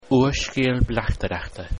If you click on these Irish words, or any of the other words of the day, you can hear how to pronounce them.